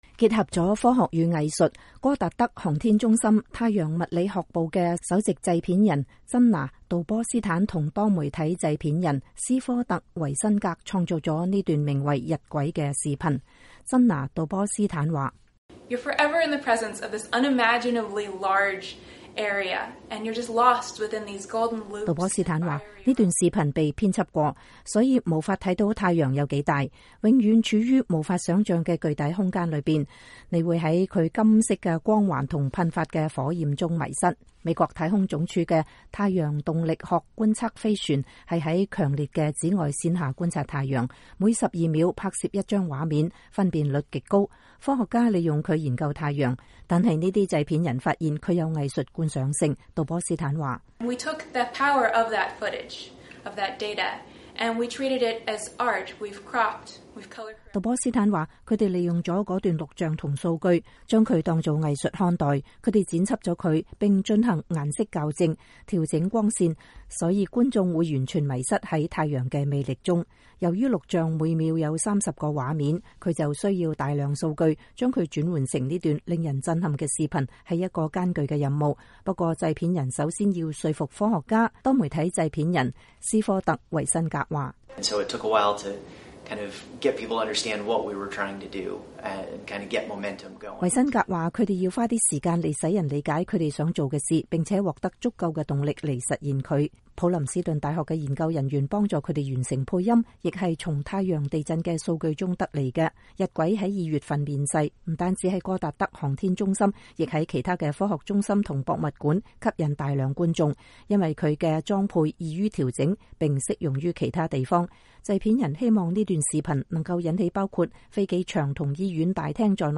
普林斯頓大學的研究人員幫助他們完成了配音，也是從太陽地震的數據中得來的。